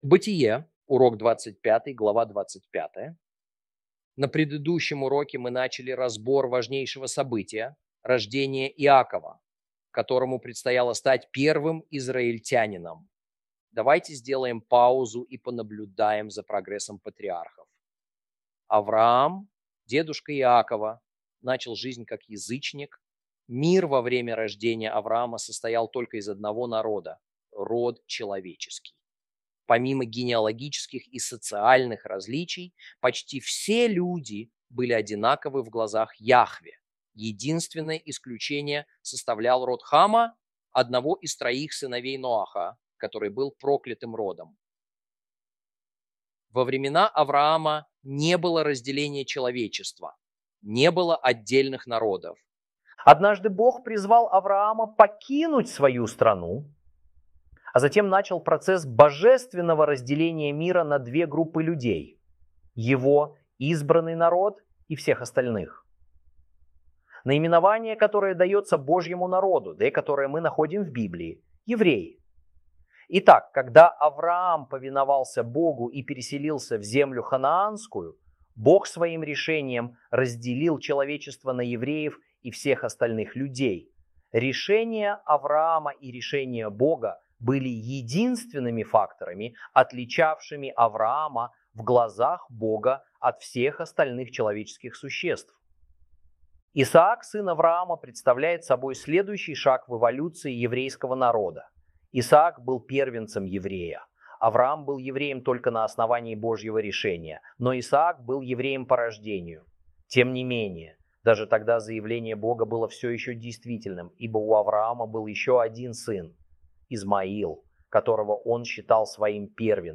Урок 25 - Бытие́ 25 - Torah Class